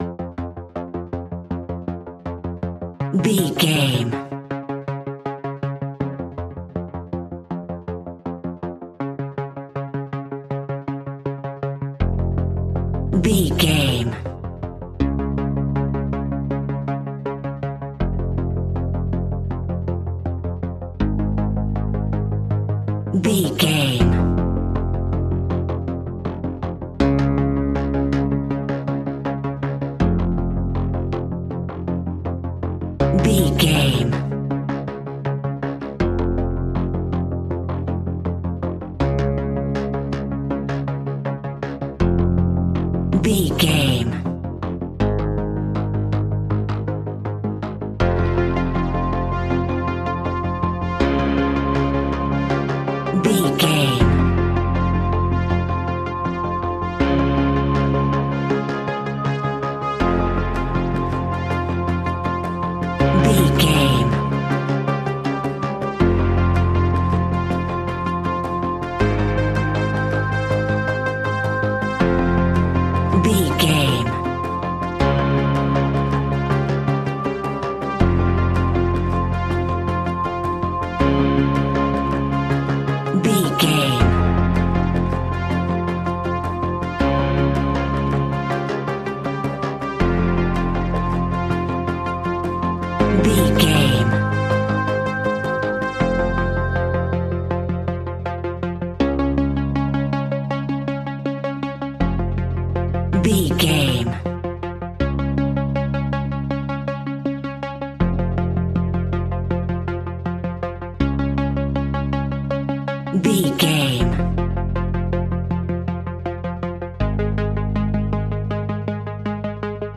Aeolian/Minor
scary
ominous
dark
haunting
eerie
industrial
synthesiser
drums
horror music